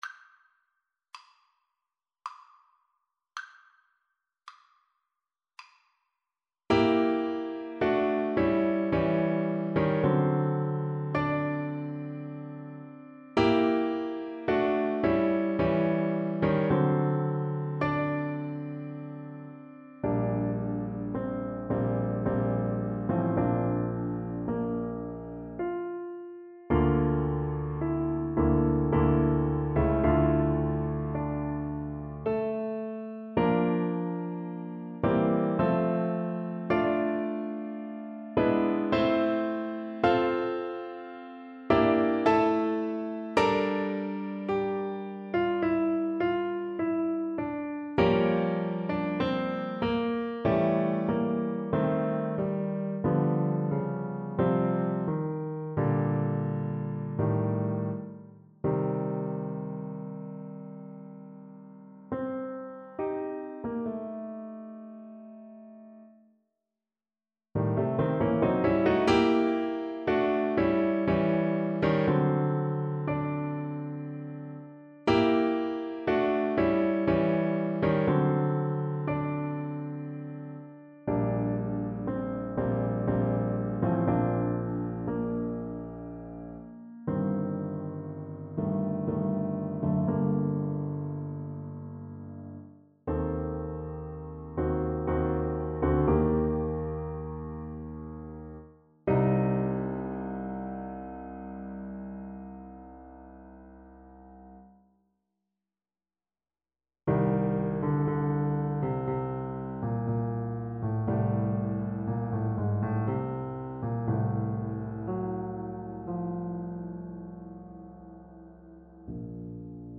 Free Sheet music for Clarinet
Clarinet
3/4 (View more 3/4 Music)
Bb major (Sounding Pitch) C major (Clarinet in Bb) (View more Bb major Music for Clarinet )
Adagio lamentoso = 54
Classical (View more Classical Clarinet Music)
Classical Tear-jerkers for Clarinet